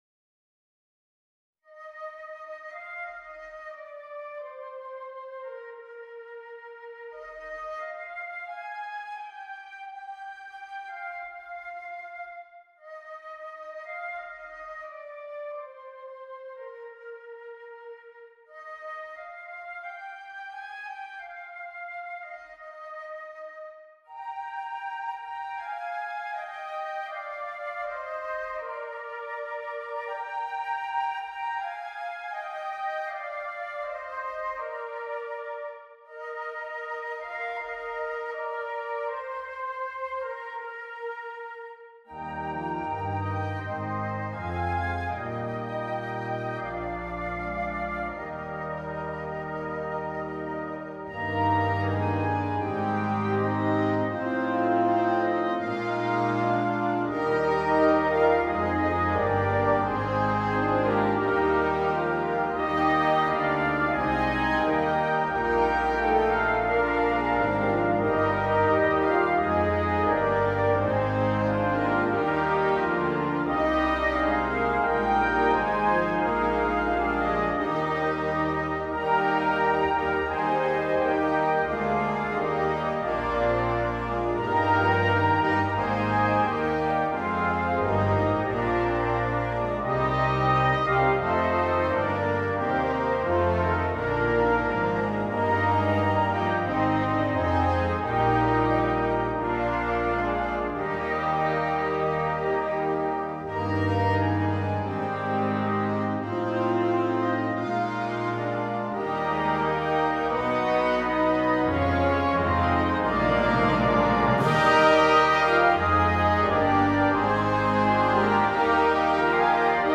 Instrumentation: Wind Band with optional Bagpipes
haunting carols